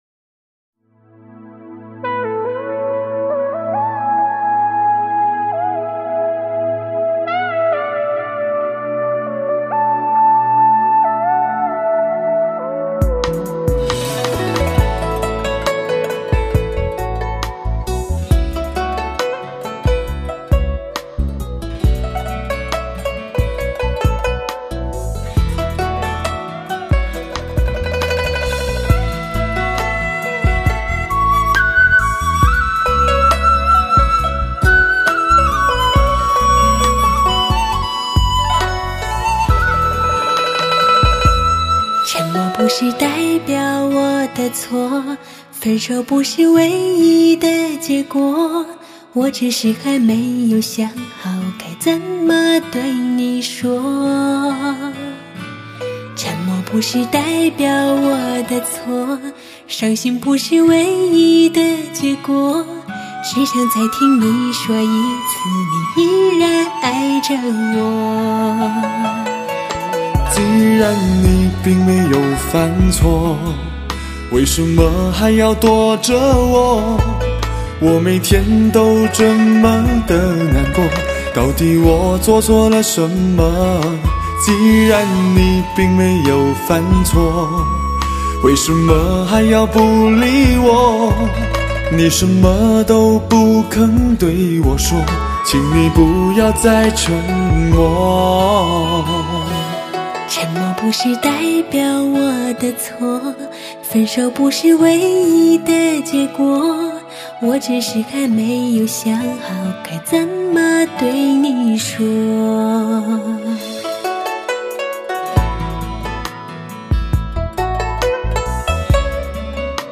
新生代中低音试音碟
至真的情感来自年轻干净与醉美的声音